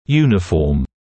[‘juːnɪfɔːm][‘юːнифоːм]единообразный, одинаковый